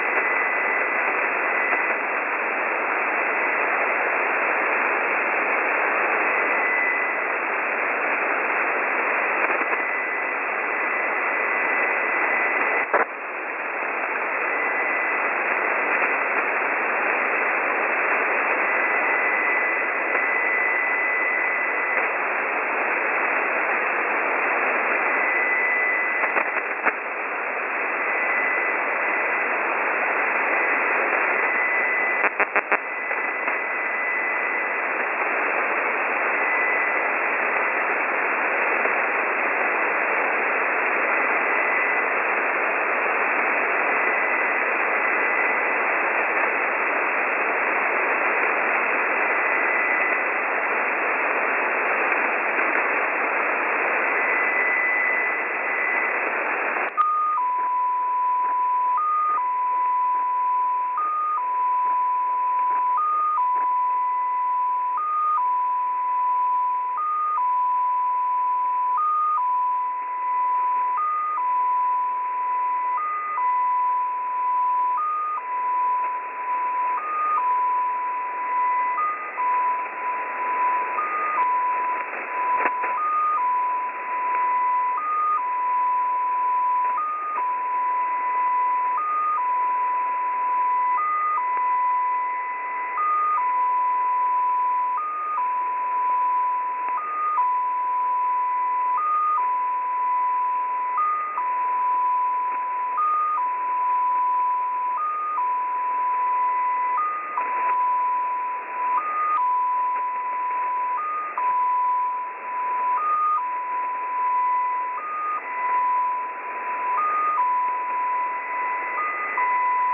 Time (UTC): 1800 Mode: USB Frequency: 14538 Recording: websdr_recording_start_2015-09-15T17_59_05Z_14538.0kHz.wav Waterfall Image: XPA2 Decoded 14538 KHz 1800z 1900 BST 150915.jpg Date (mm/dd/yy): 091515